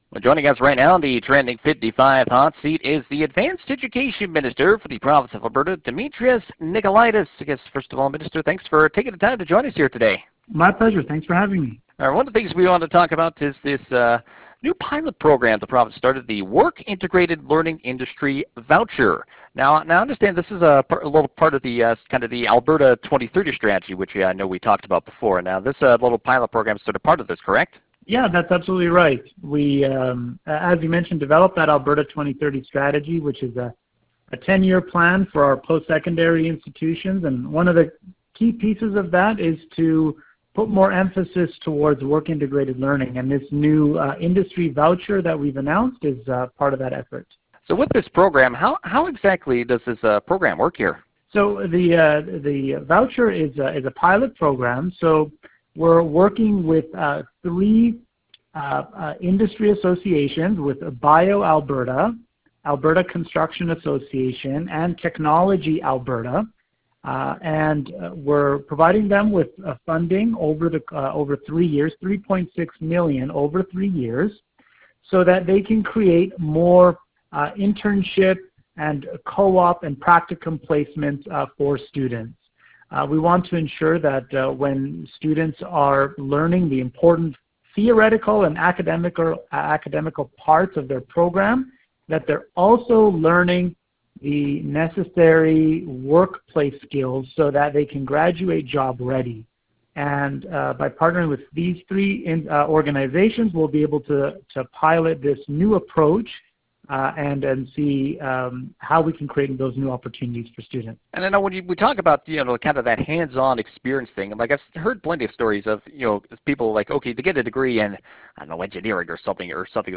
We talked to the Minister on the Trending 55 Hot Seat, you can hear the full interview below.